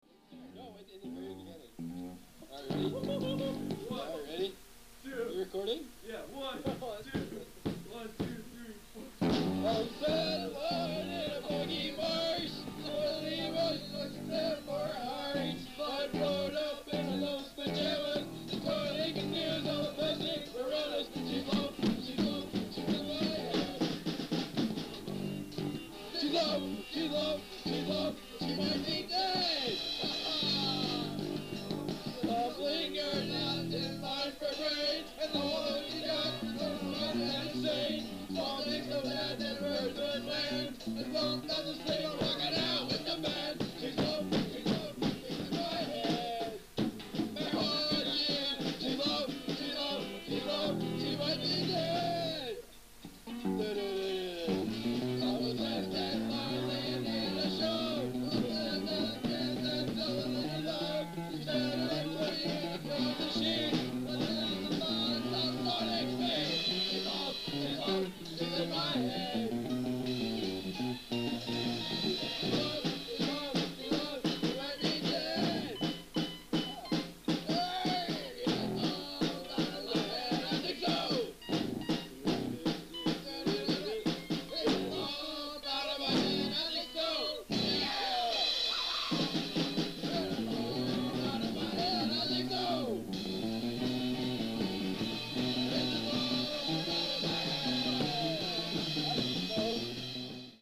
Upcoming High School Band